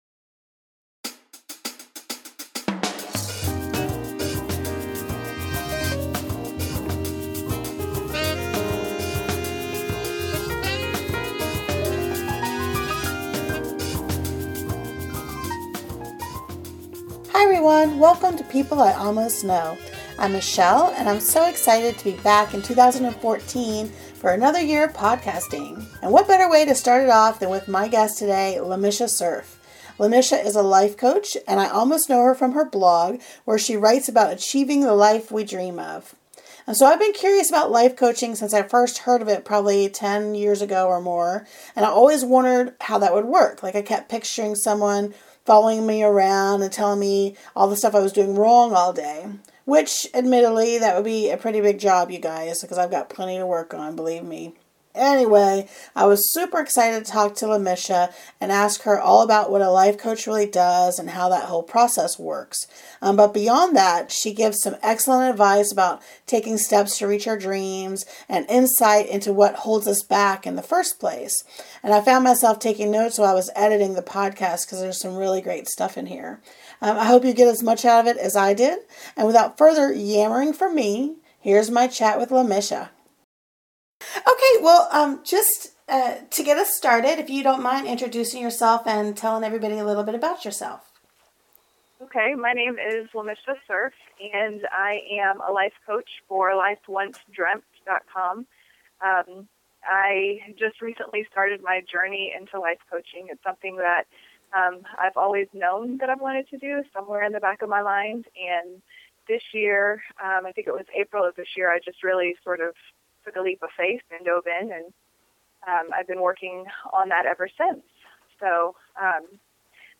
moving the podcast – interview from January 7, 2014